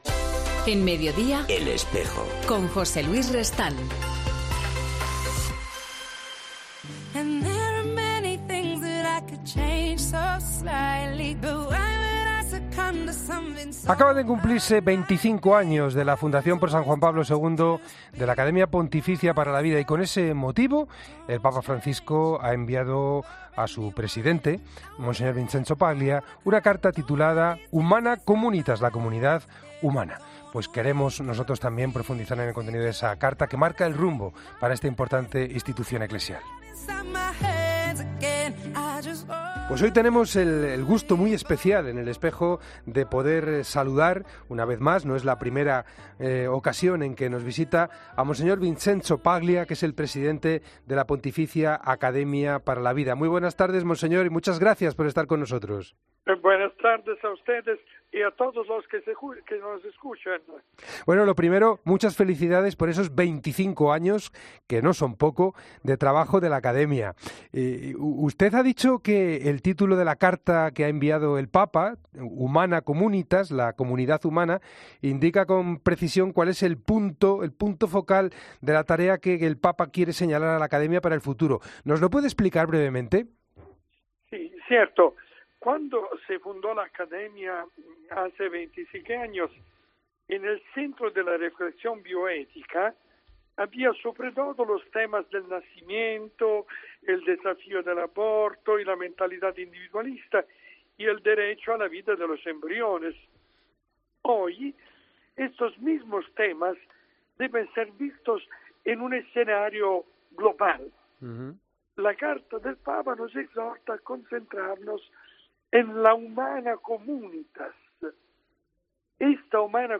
En El Espejo hablamos con el Presidente de la Academia Pontificia para la Vida, que acaba de recibir una Carta del Papa titulada "La Comunidad Humana".